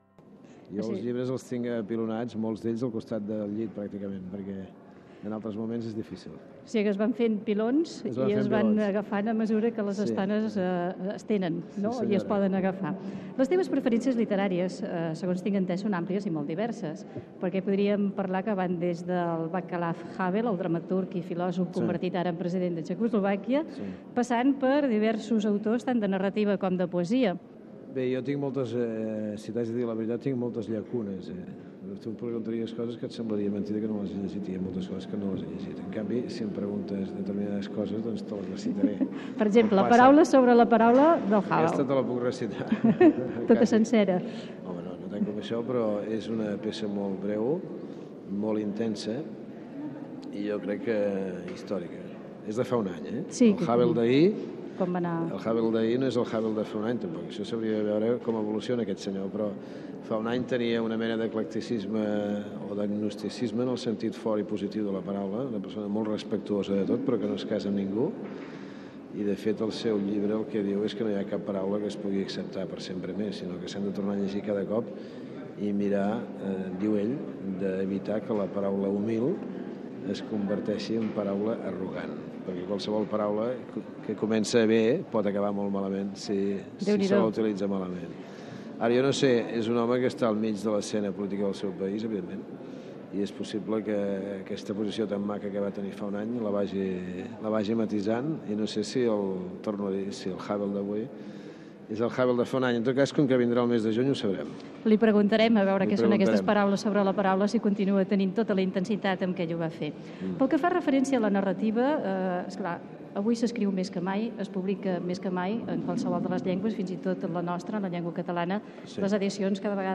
Entrevista a l'alcalde de Barcelona Pasqual Maragall feta al Palau de la Virreina a la Diada de Sant Jordi. S'hi parla de llibres i records literaris
Informatiu